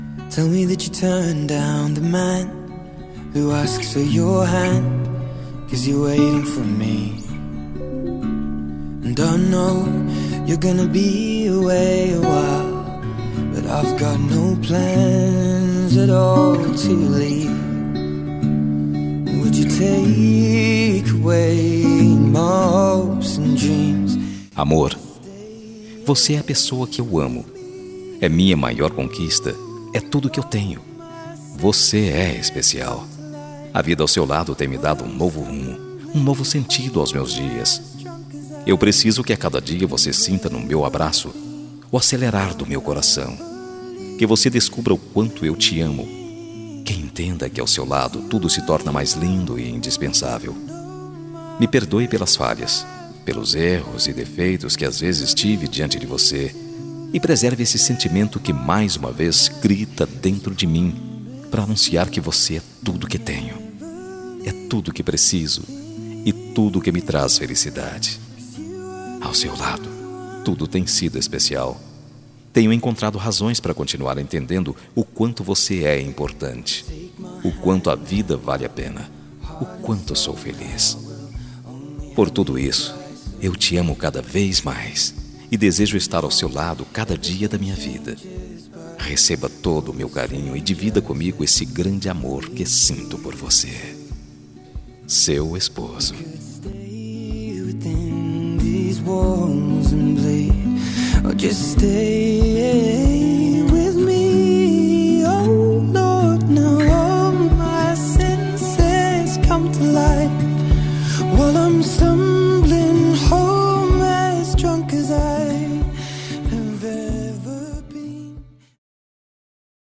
Telemensagem Romântica para Esposa – Voz Masculina – Cód: 9073